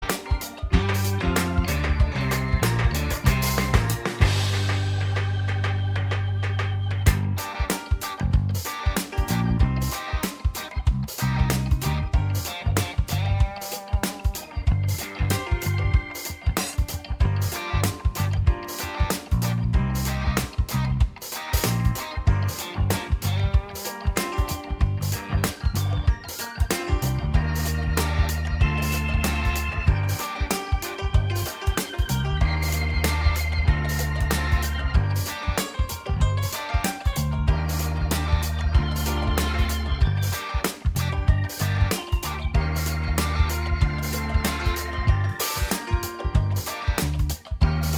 Minus Main Guitar Soft Rock 4:36 Buy £1.50